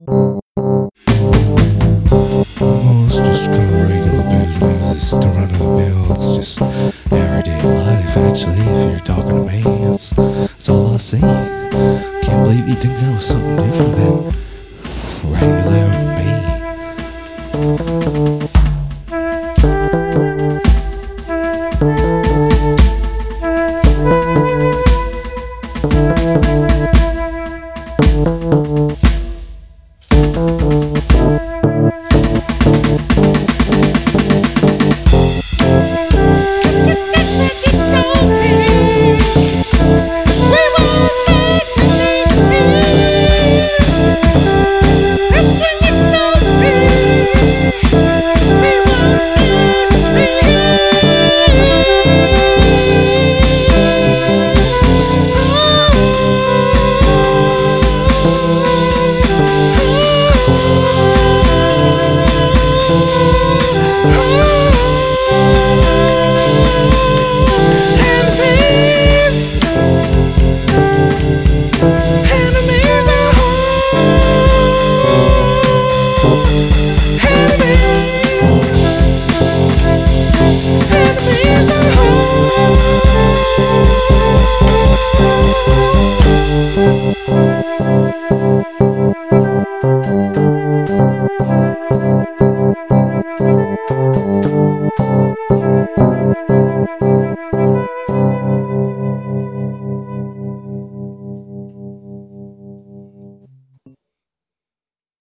Progressive Orchestral
Synthesizer
Woodwind
Drums
Main Vocals